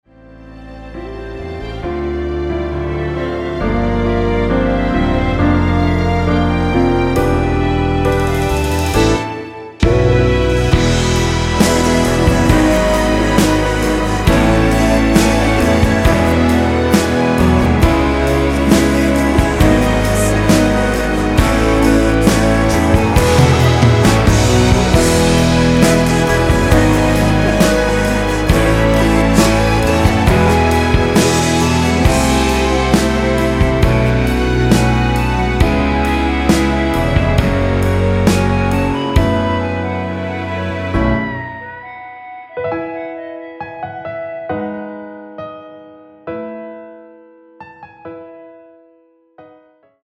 이곡의 코러스는 미리듣기에 나오는 부분밖에 없으니 참고 하시면 되겠습니다.
원키 멜로디와 코러스 포함된 MR입니다.(미리듣기 확인)
앞부분30초, 뒷부분30초씩 편집해서 올려 드리고 있습니다.
중간에 음이 끈어지고 다시 나오는 이유는